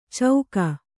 ♪ cauka